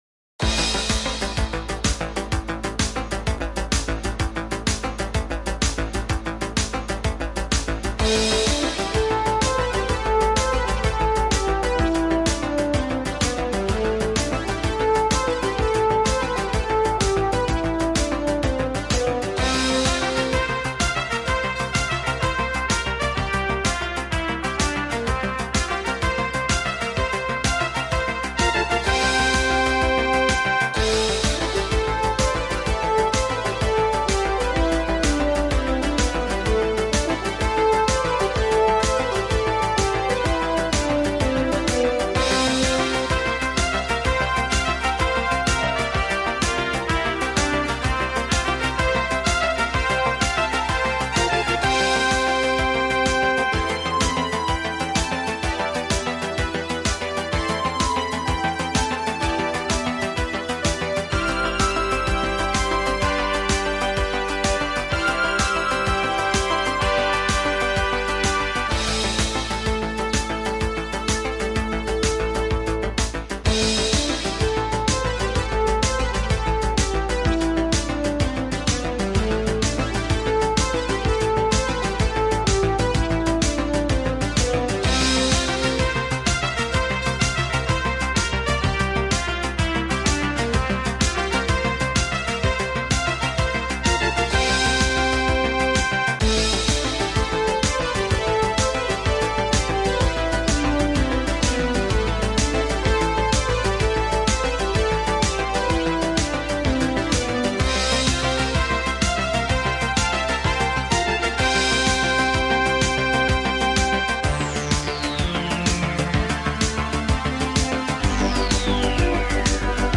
Airwolf Bell 222 Helicopter sound effects free download